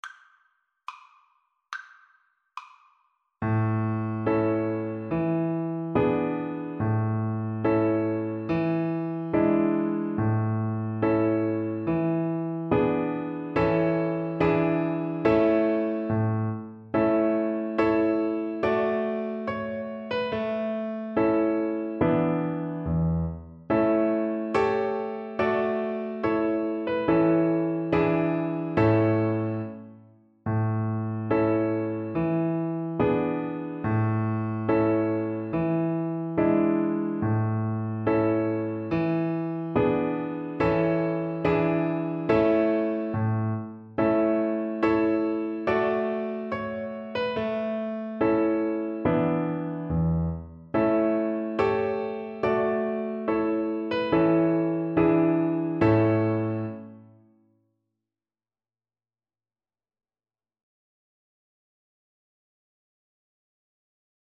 Violin
A major (Sounding Pitch) (View more A major Music for Violin )
Allegretto
2/4 (View more 2/4 Music)
Traditional (View more Traditional Violin Music)